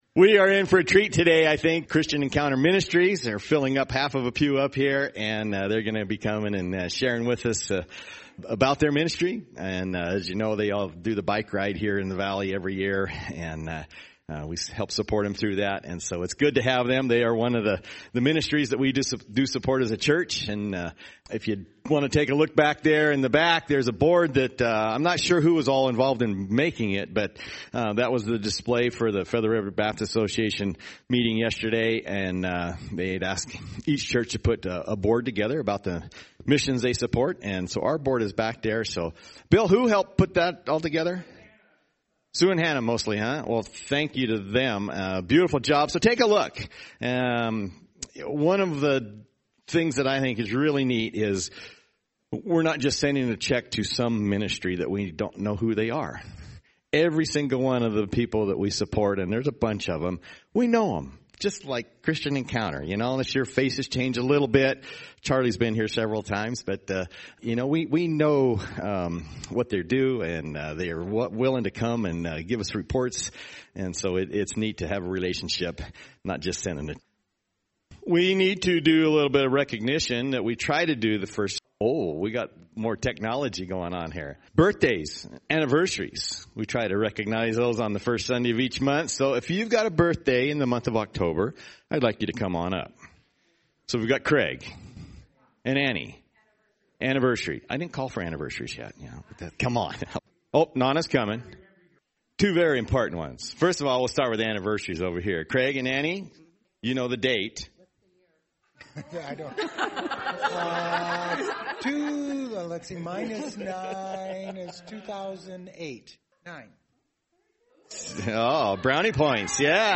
Sermons - Community Church of Loyalton